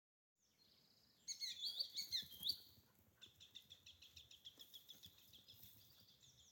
Птицы -> Kулики ->
черныш, Tringa ochropus
ПримечанияAizlido virs upes